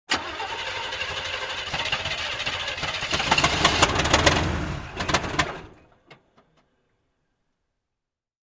Звуки не заводящейся машины
Завелась, но не завелась